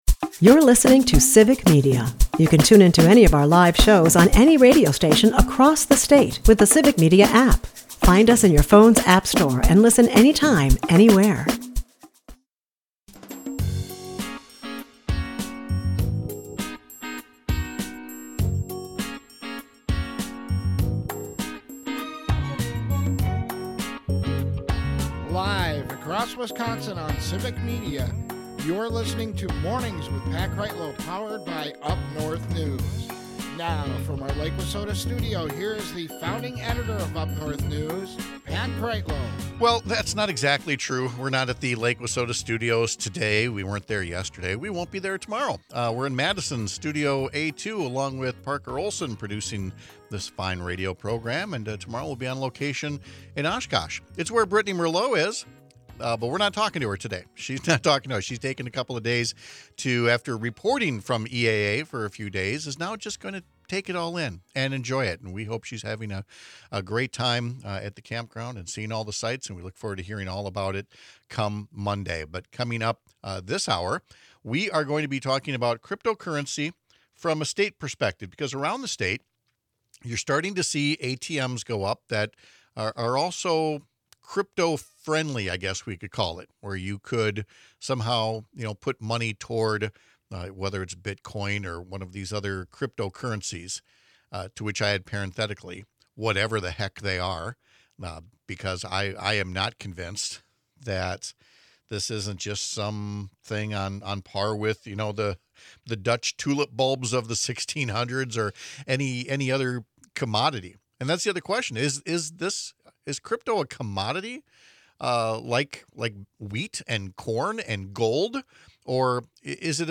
Two state legislators outline their bill designed to put some guardrails for consumers on these crypto boxes in our state. Mornings with Pat Kreitlow is powered by UpNorthNews, and it airs on several stations across the Civic Media radio network, Monday through Friday from 6-9 am.